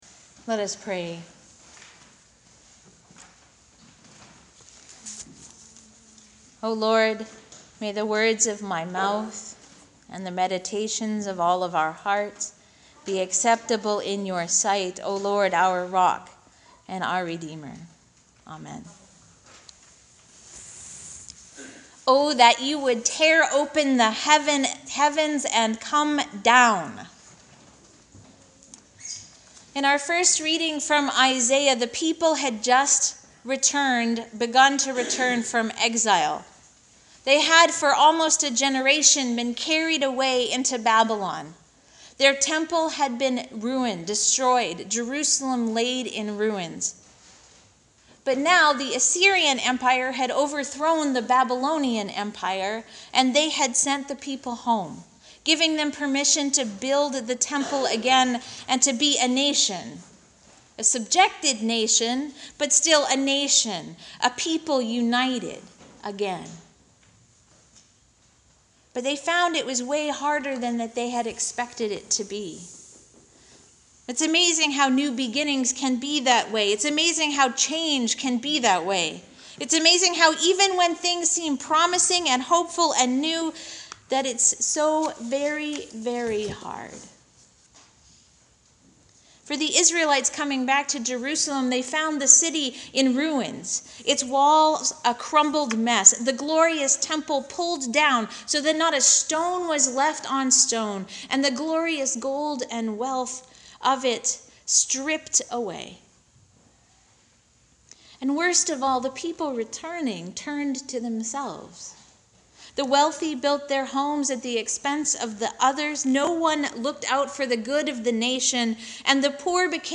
Audio of sermon:
sermon-advent-1b.mp3